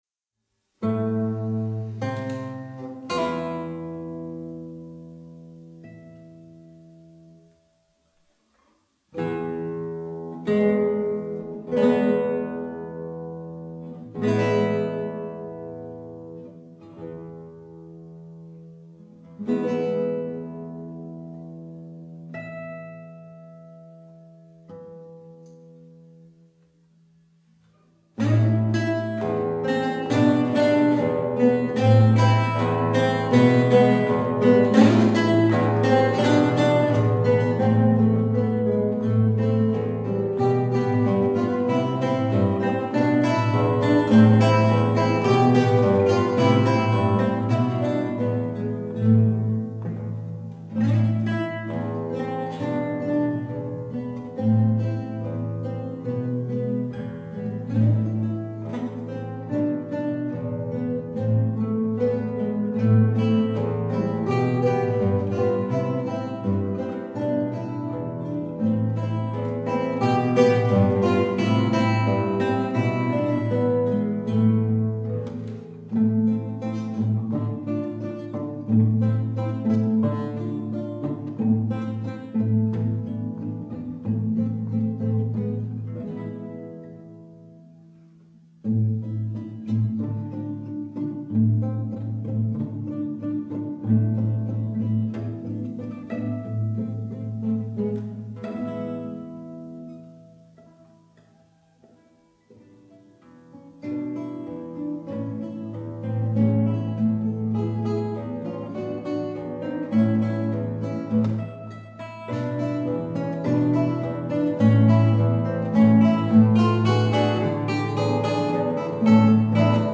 Pasodoblillo 2.50